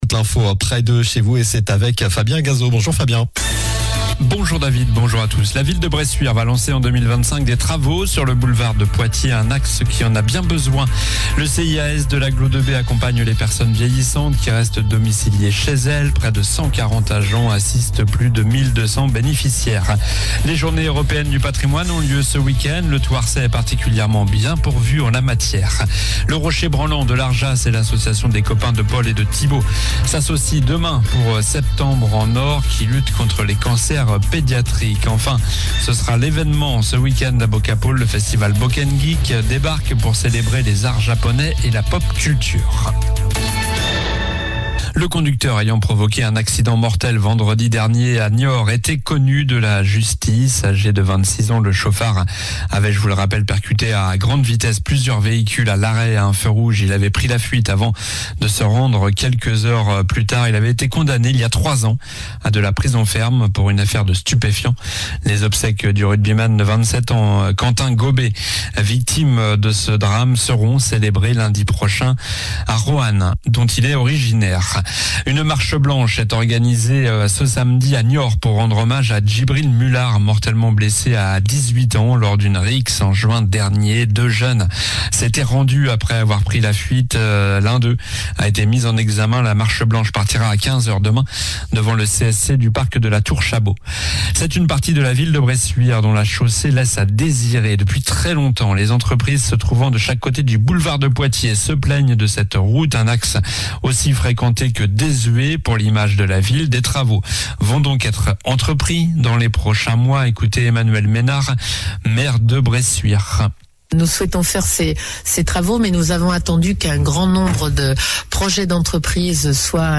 Journal du vendredi 20 septembre (midi)